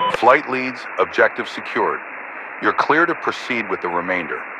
Radio-commandObjectiveComplete3.ogg